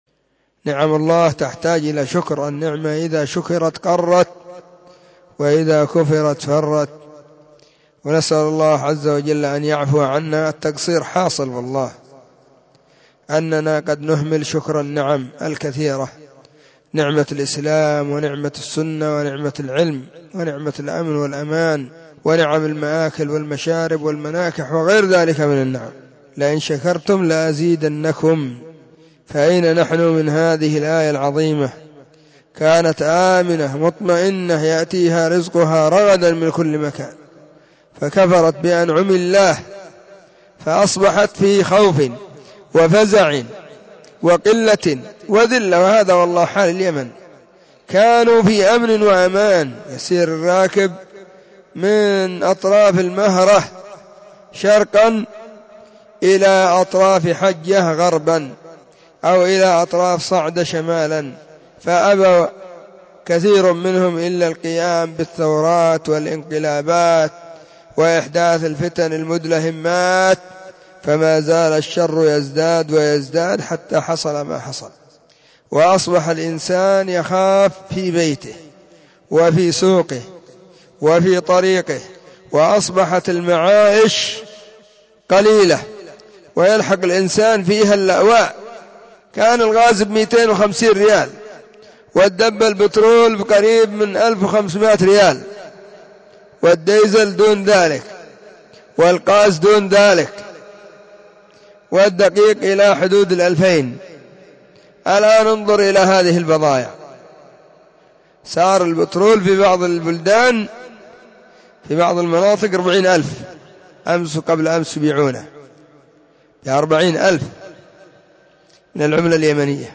📢 مسجد الصحابة بالغيضة, المهرة، اليمن حرسها الله.